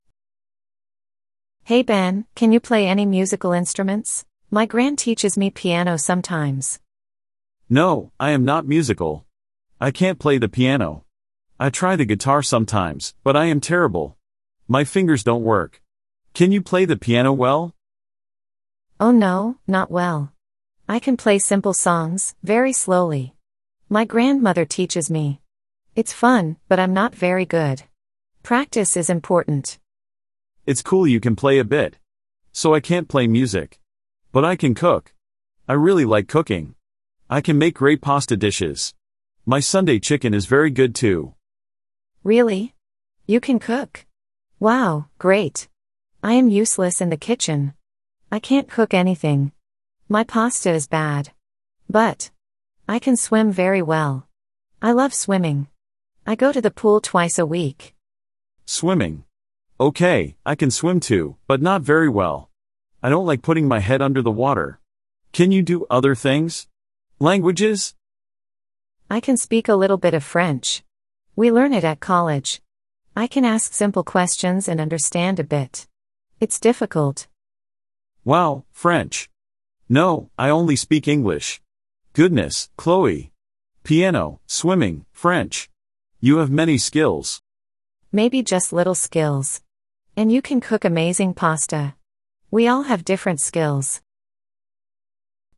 Beginner Listening Practice
Listen to Chloe and Ben compare their skills and abilities.